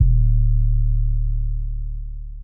Lex808.wav